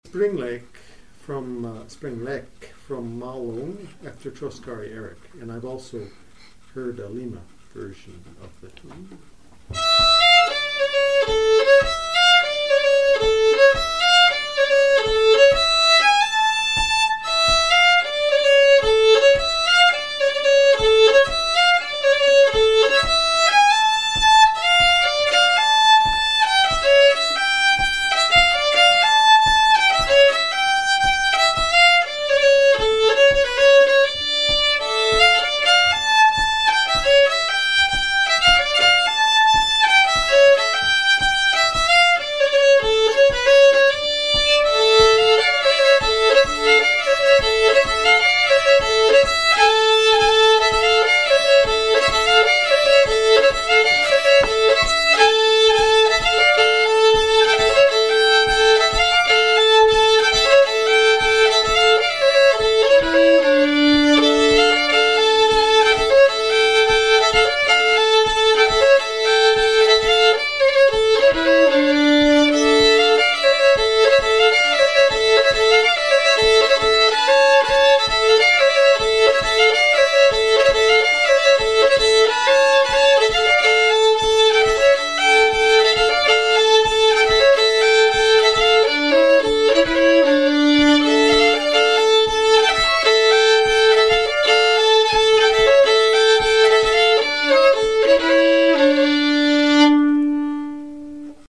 Malungspolska 2